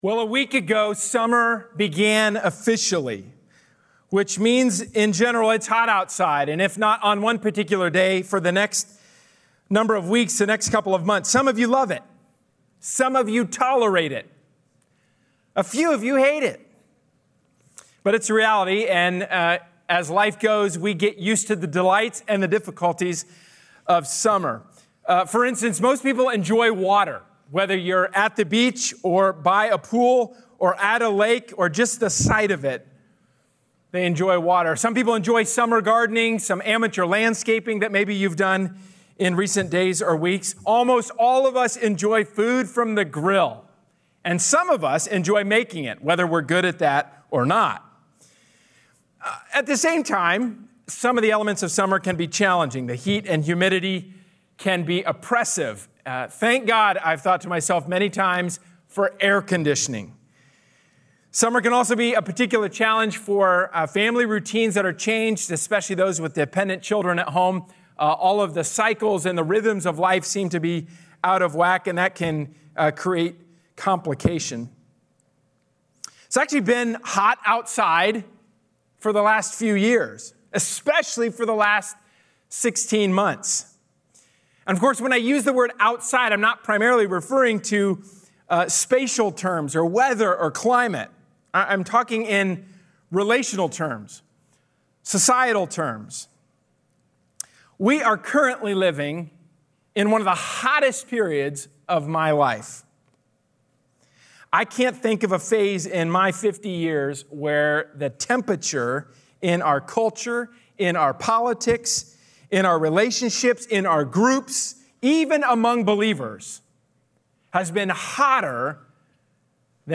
A sermon from the series "Hot Topics."